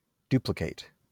IPA/ˈdjuː.plɪˌkeɪt/, SAMPA/"dju:.plI%keIt/
wymowa amerykańska?/i